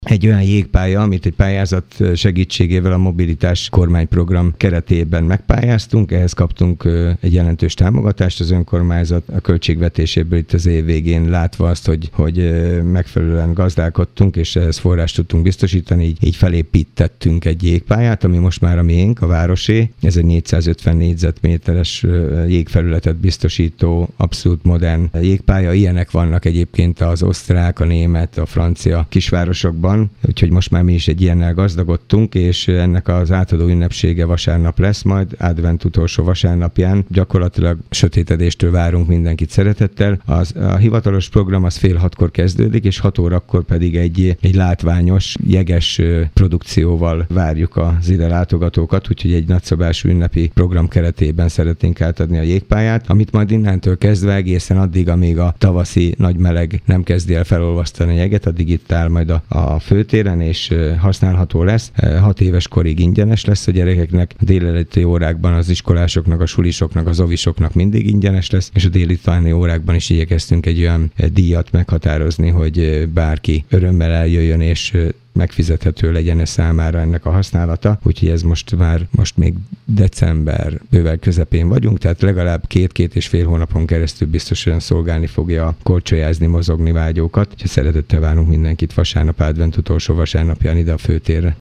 Kőszegi Zoltán polgármester azt mondta, új sportág kerül fel a város palettájára.